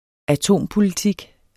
Udtale [ aˈtoˀmpoliˌtig ]